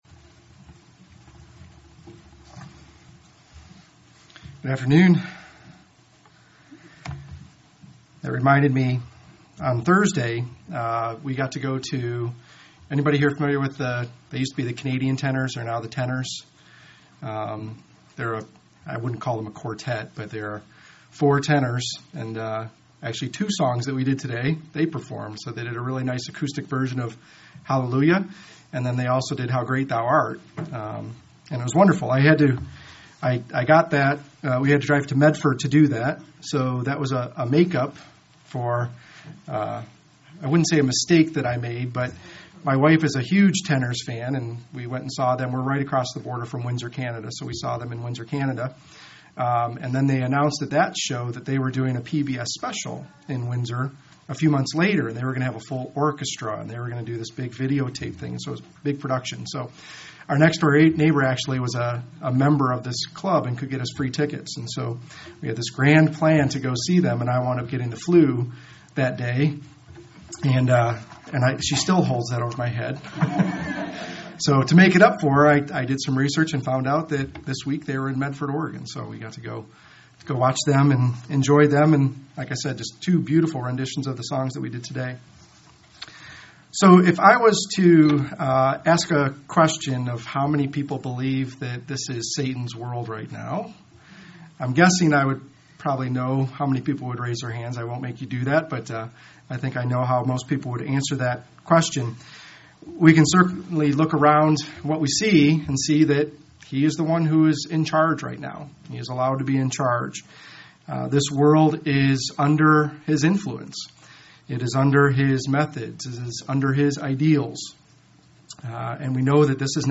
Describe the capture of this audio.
Given in Central Oregon